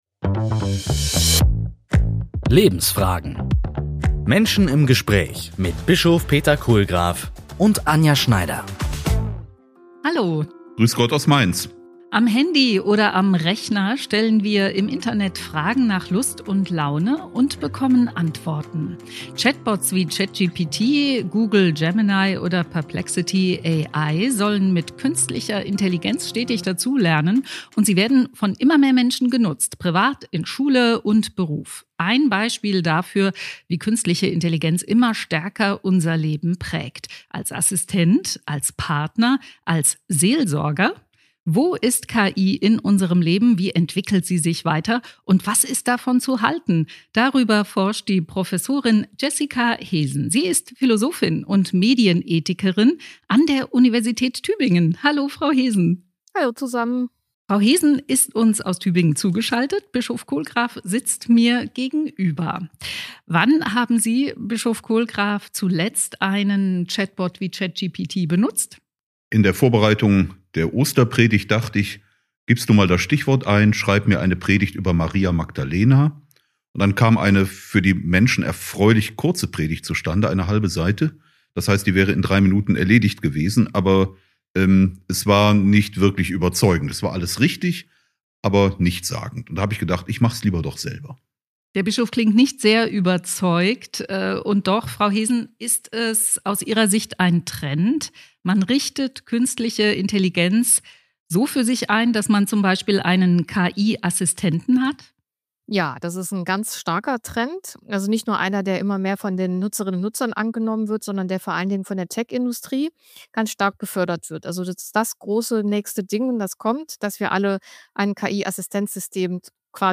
Lebensfragen - Menschen im Gespräch